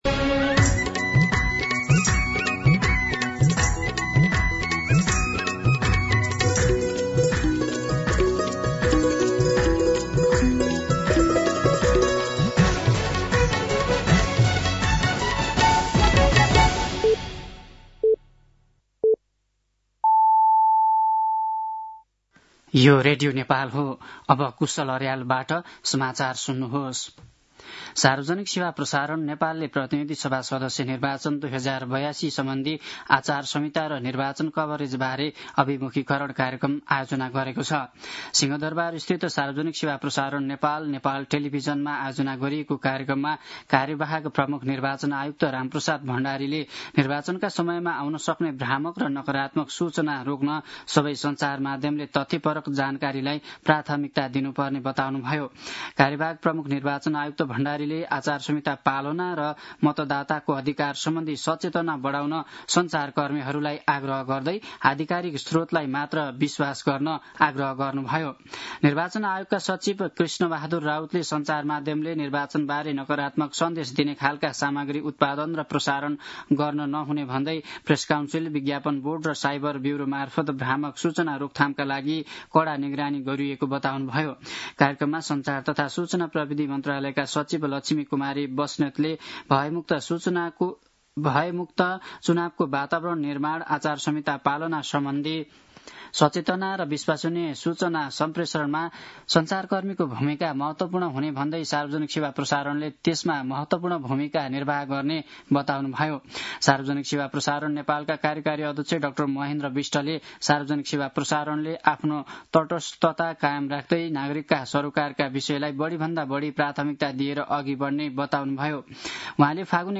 दिउँसो ४ बजेको नेपाली समाचार : १२ माघ , २०८२
4pm-News-10-12-.mp3